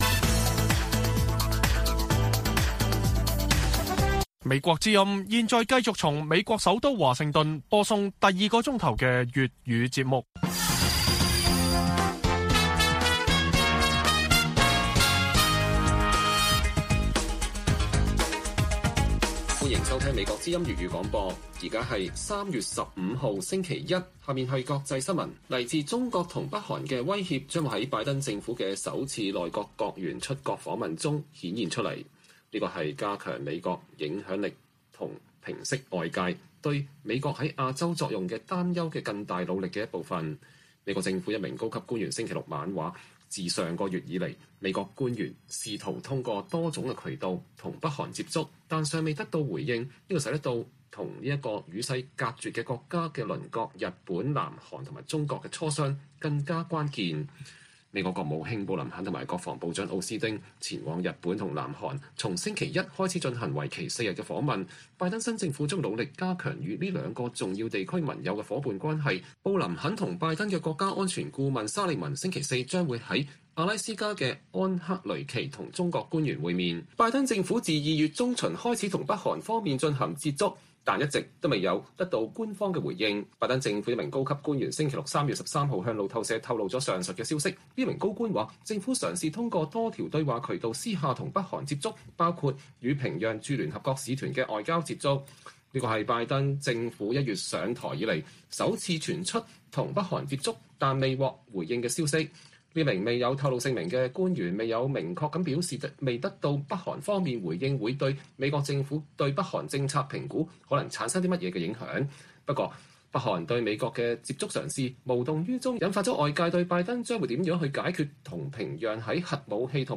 粵語新聞 晚上10-11點